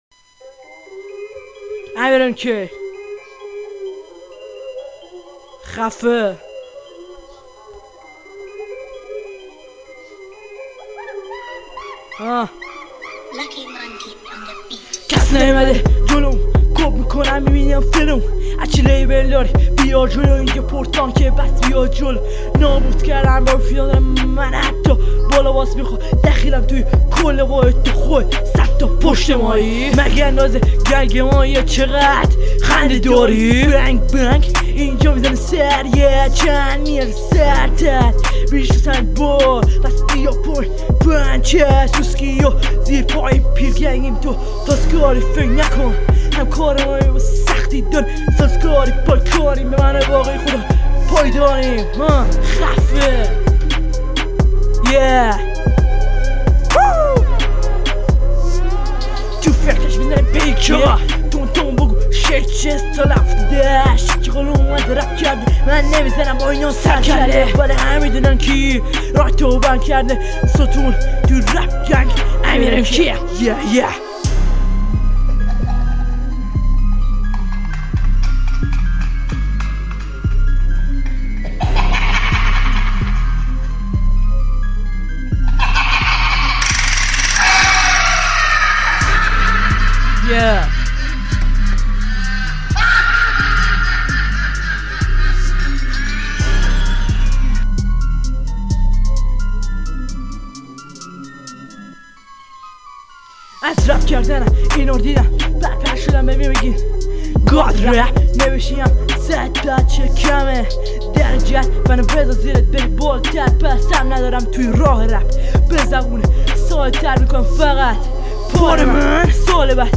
نوع آهنگ:گنگ